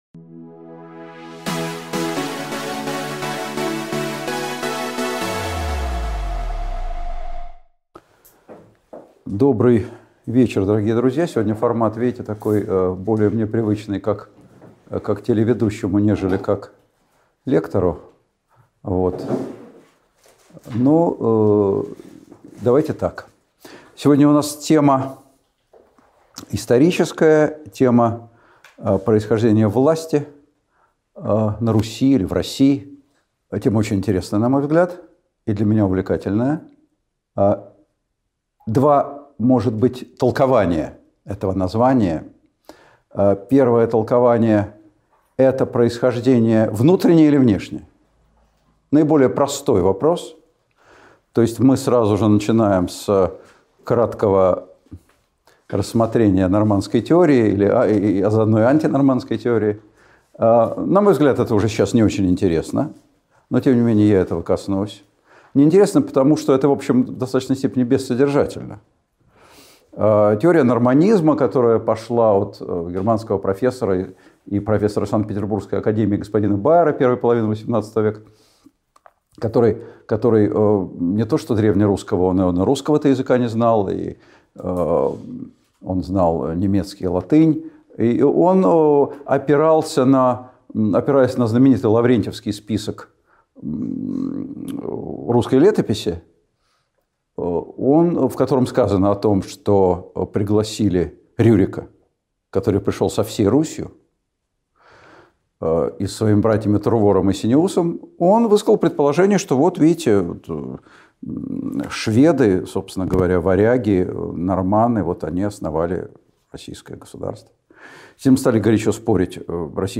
Автор Николай Сванидзе Читает аудиокнигу Николай Сванидзе.